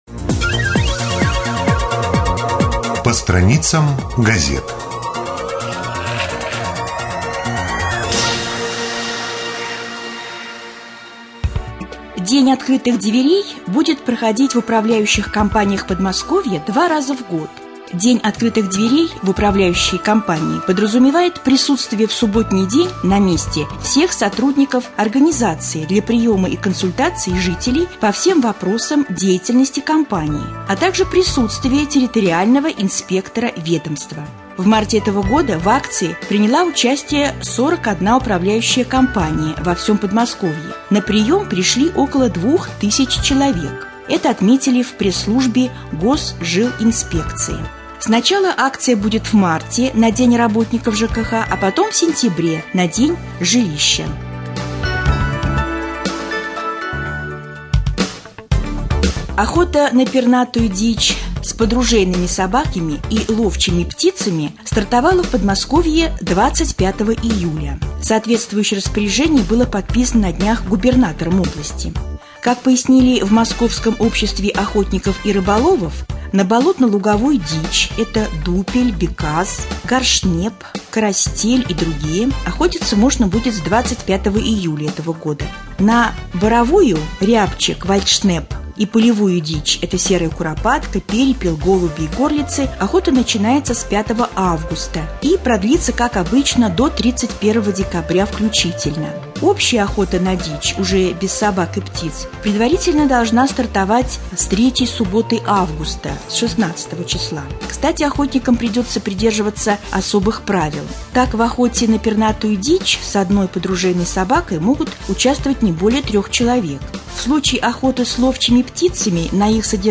31.07.2014 в эфире Раменского радио - РамМедиа - Раменский муниципальный округ - Раменское